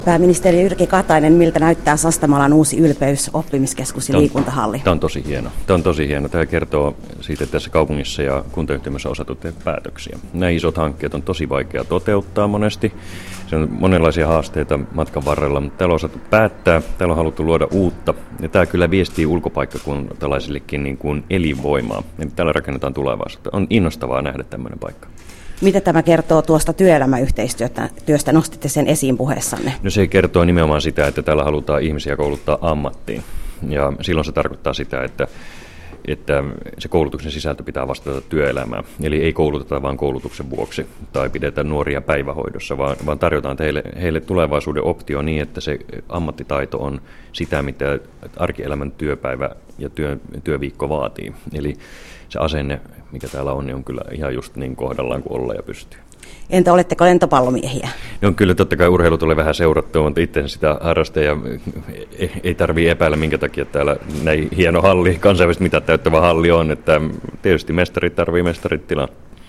Jyrki-Katainen-Sastamalassa.mp3